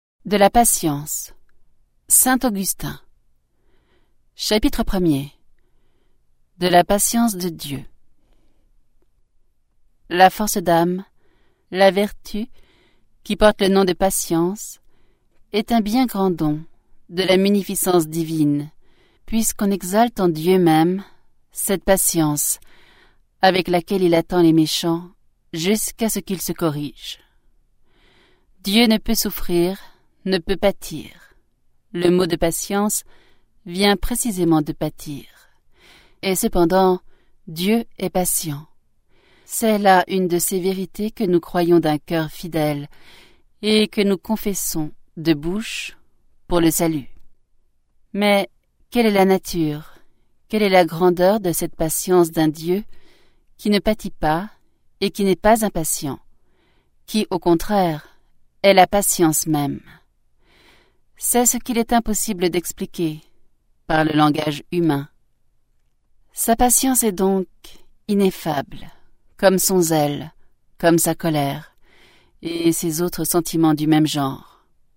je découvre un extrait - De la patience de Saint Augustin